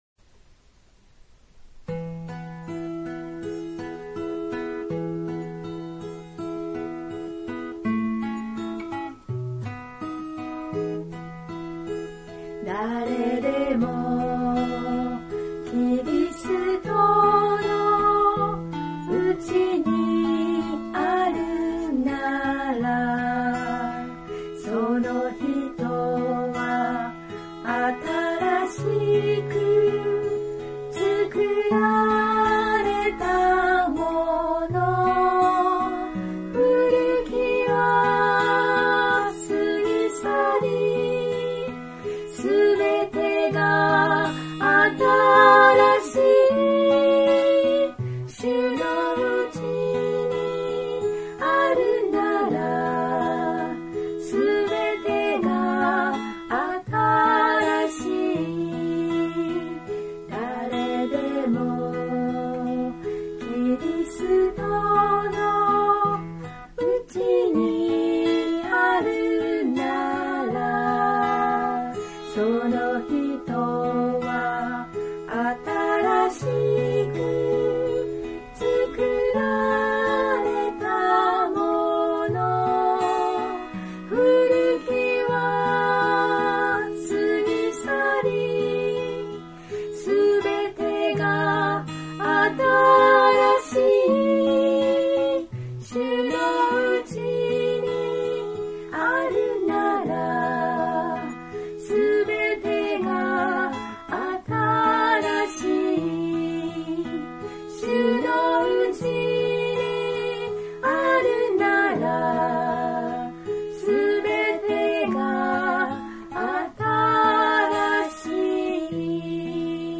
（徳島聖書キリスト集会集会員）